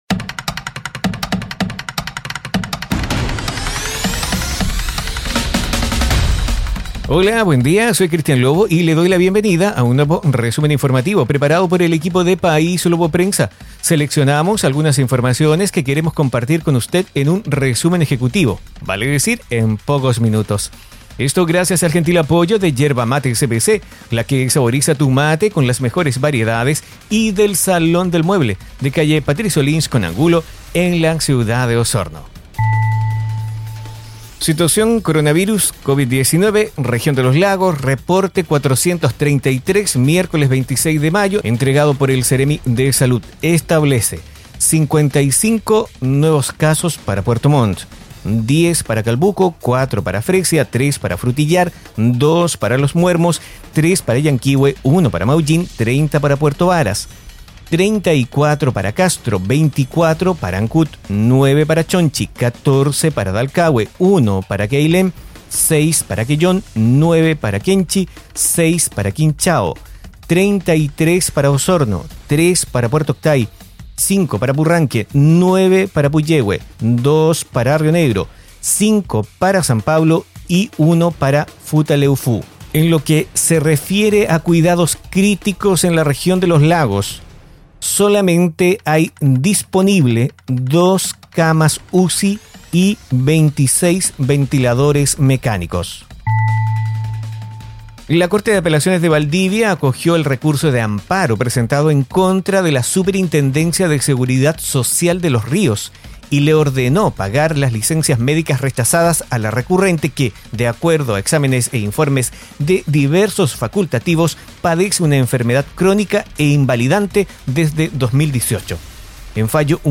Informaciones enfocadas en la Región de Los Lagos. Difundido en radios asociadas.